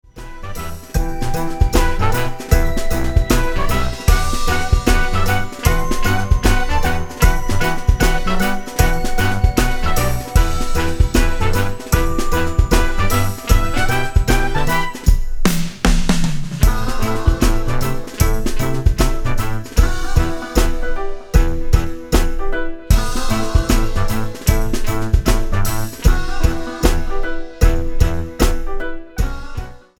78 BPM